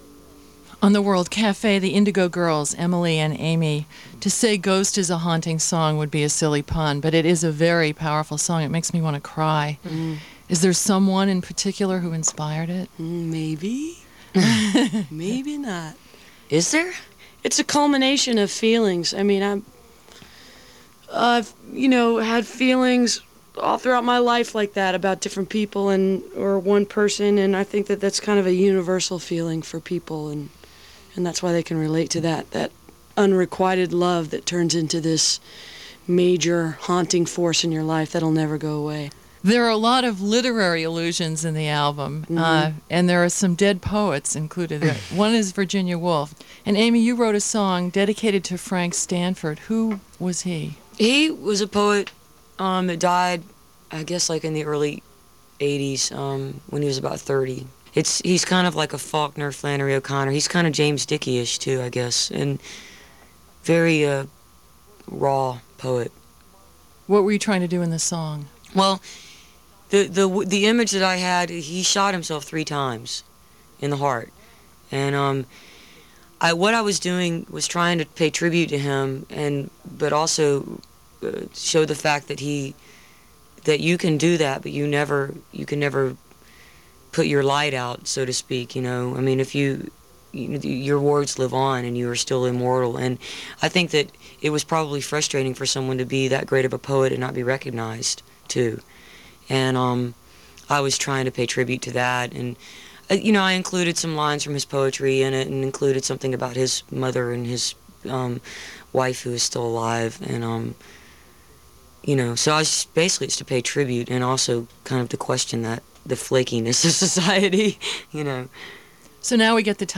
lifeblood: bootlegs: 1992-06-26: world cafe radio show
05. interview (2:04)